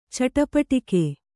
♪ caṭapaṭike